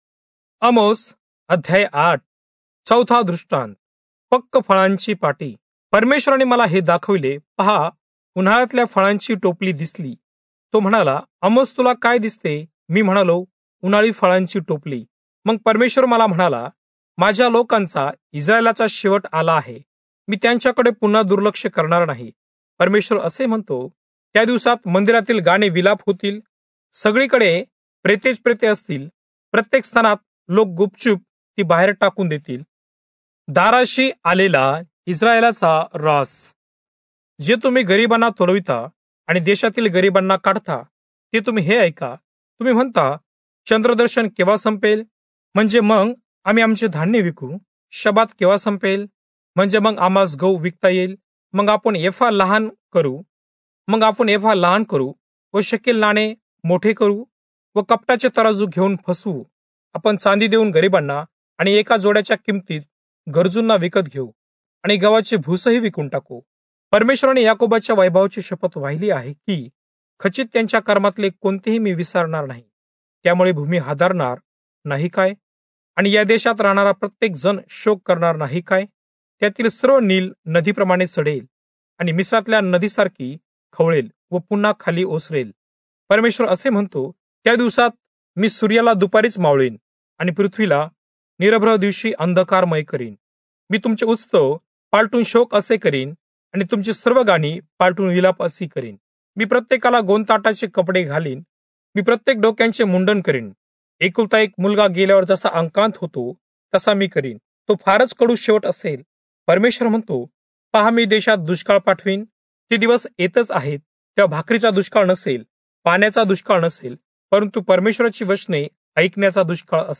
Marathi Audio Bible - Amos 9 in Irvmr bible version